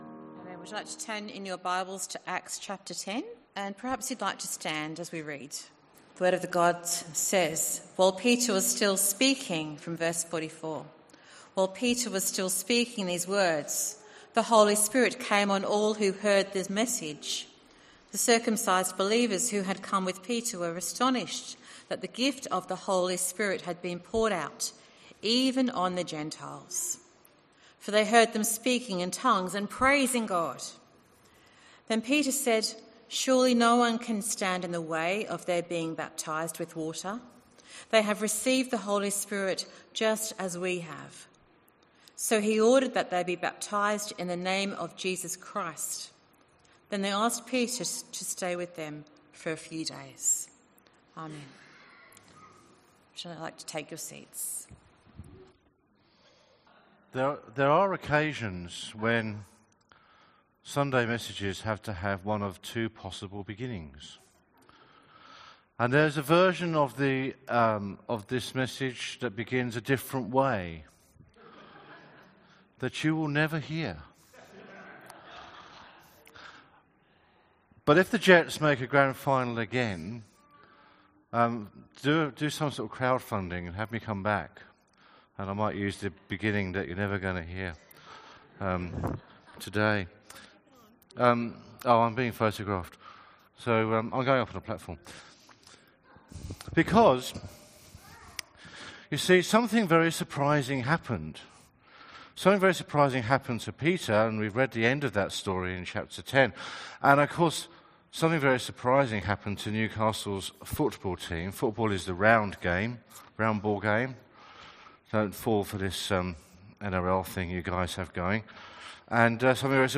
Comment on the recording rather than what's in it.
Bible reading and sermon from the 10AM meeting at Newcastle Worship & Community Centre of The Salvation Army. The Bible reading is taken from Acts 10:44-48.